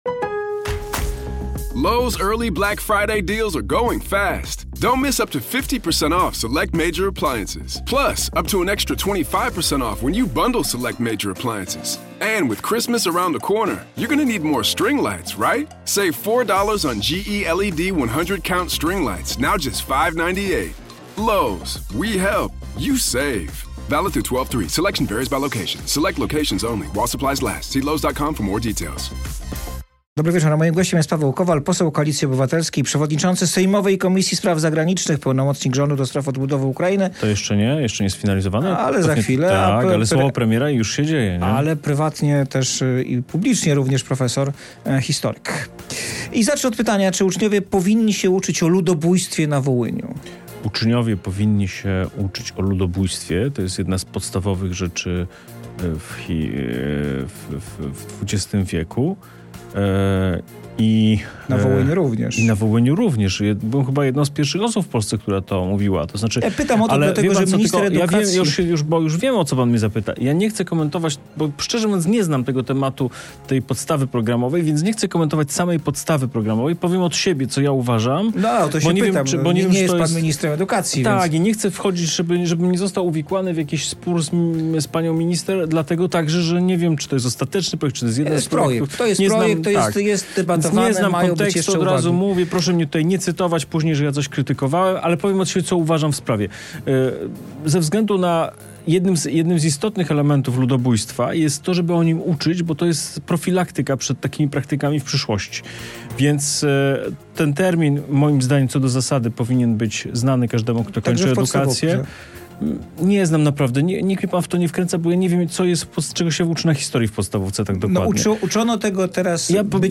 08:00 Fakty i Poranna rozmowa w RMF FM - 25.09.2024